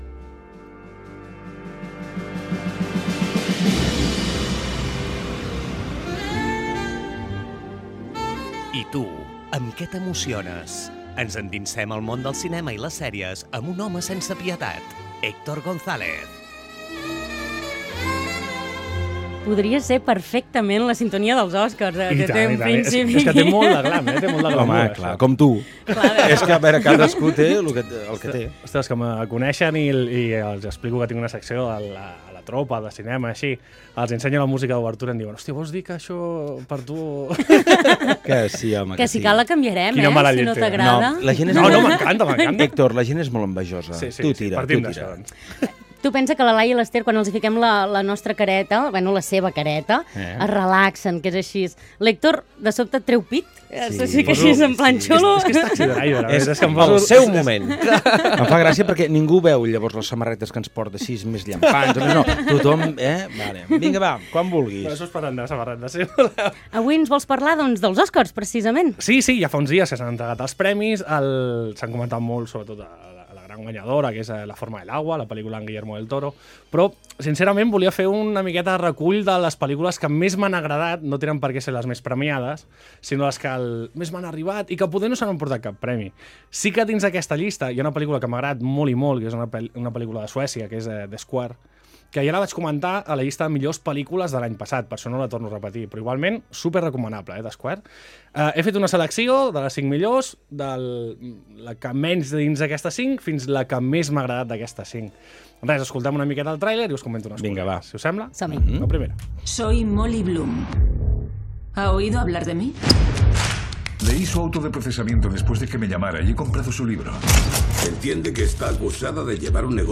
Siete secciones llevo ya colaborando con el programa “La Tropa” de la emisora de radio “Fem Girona”. En esta ocasión aprovecho para escoger las películas que más me han gustado de esta edición de los Óscar independientemente de los premios ganados.